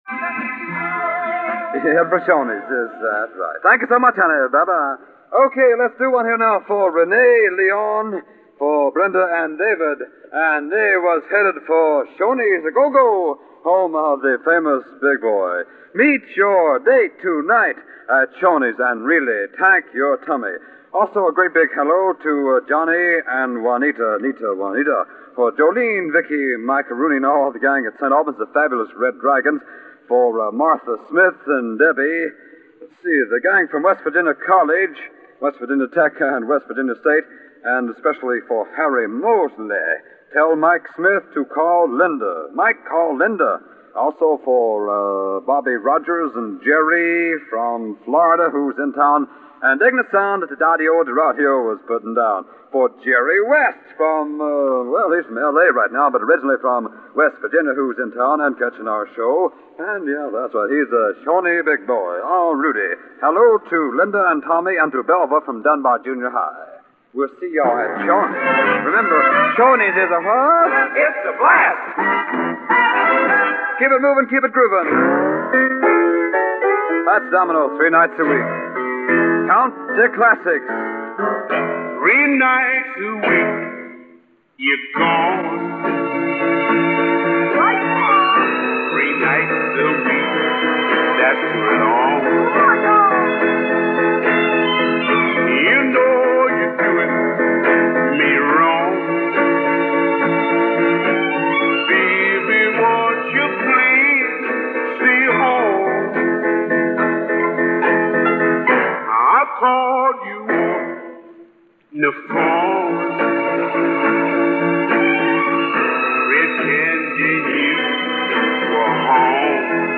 Commercials, ID's, and just nonsense .
More Dedications
Viet Nam Dedications and Station Spot